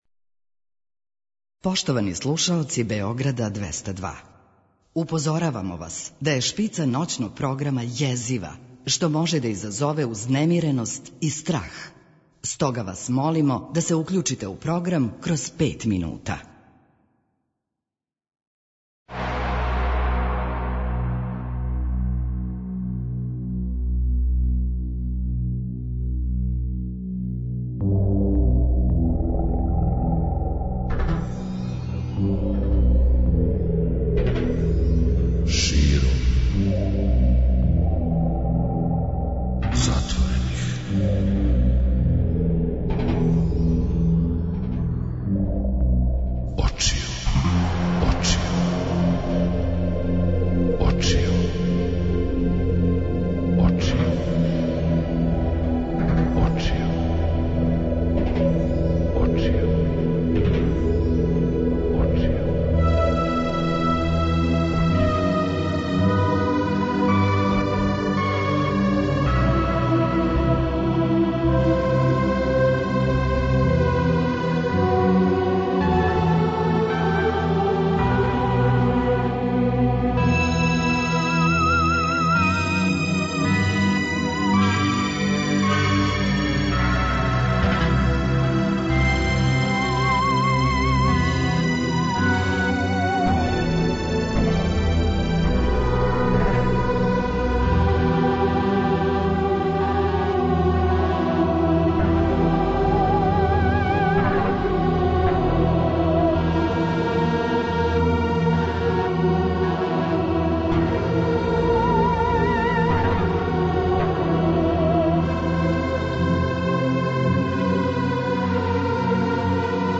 На таласима Београда 202 у оквиру ноћног програма Широм затворених очију емитоваће се Бајка.
И ноћас, уз обиље добре музике пловићемо таласима Ваше и наше ДВЕСТАДВОЈКЕ кроз низ бајковитих тема, јер једноставно речено ако бар мало не верујемо у бајке, у неко лепо, прошло време, ово садашње прогутаће нас дланом о длан.